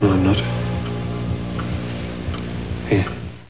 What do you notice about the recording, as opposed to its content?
Sounds were originally sampled at 22 kHz, 16-bit mono with GoldWave, then resampled to 11 kHz, 8-bit mono to reduce their file size.